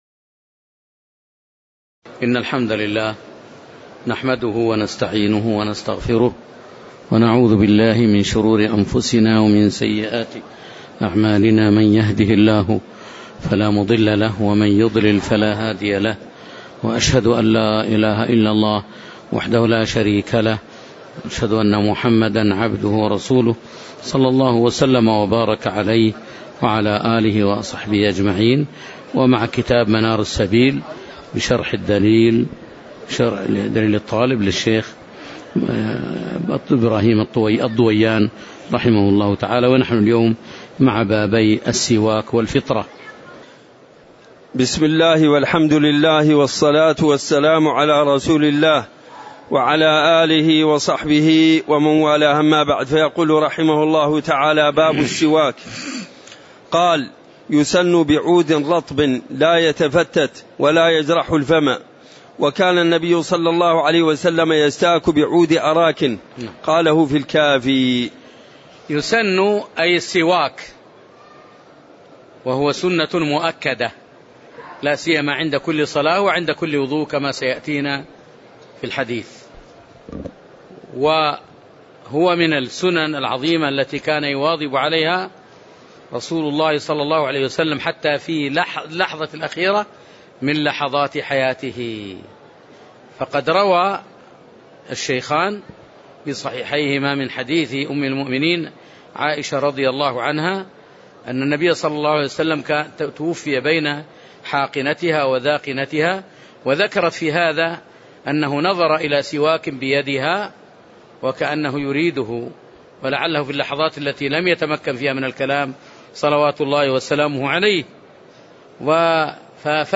تاريخ النشر ٢٣ جمادى الآخرة ١٤٣٨ هـ المكان: المسجد النبوي الشيخ